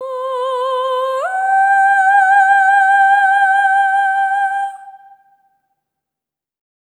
SOP5TH C5 -R.wav